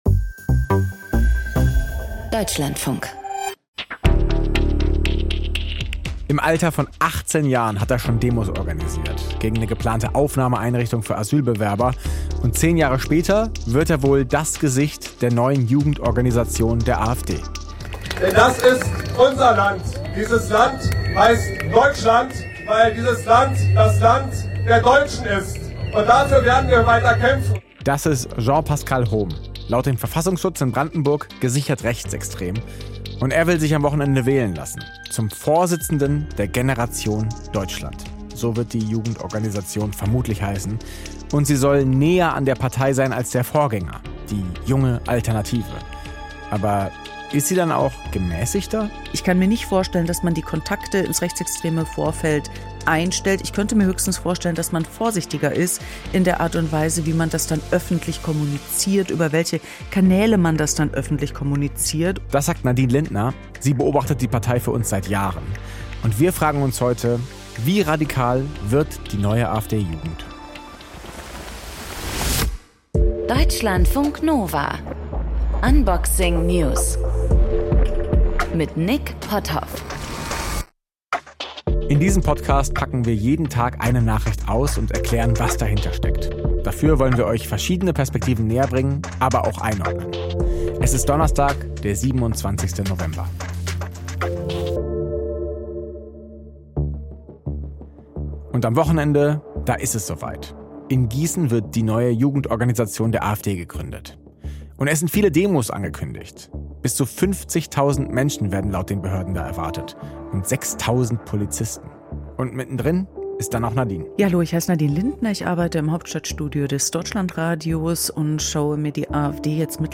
Ist die AfD eine Gefahr für die Demokratie in Deutschland? Welchen Einfluss versucht die MAGA in Europa geltend zu machen? Dazu im Gespräch: zwei Journalisten.